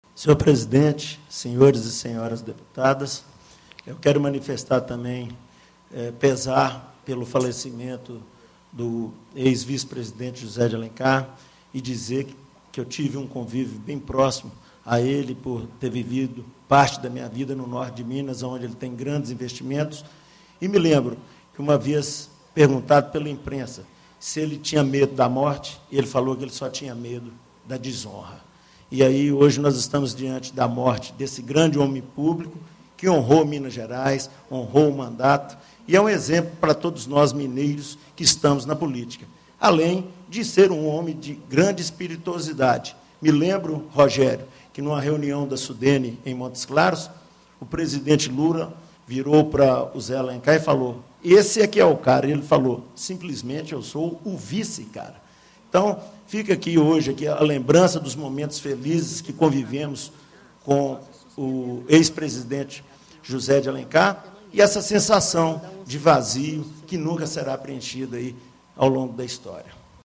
Reunião de Plenário - Pronunciamento sobre o ex-vice-presidente da república, José Alencar